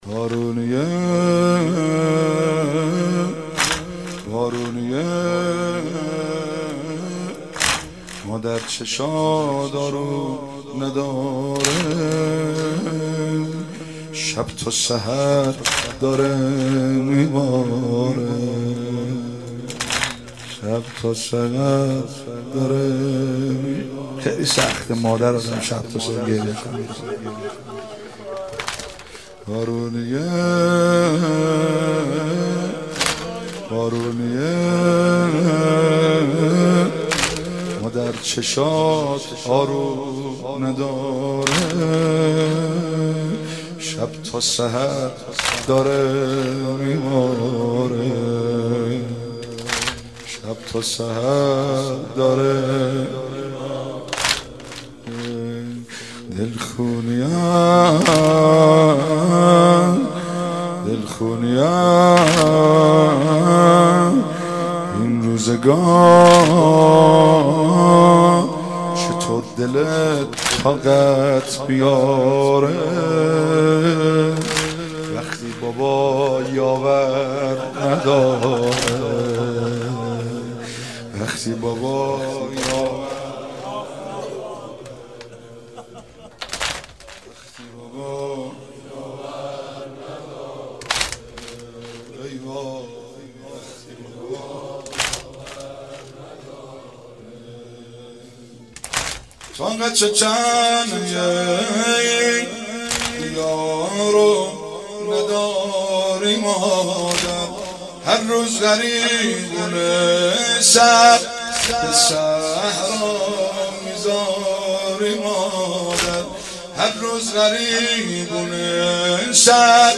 شب دوم فاطمیه 95 - واحد - مادر چشات آروم نداره